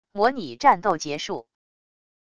模拟战斗结束wav音频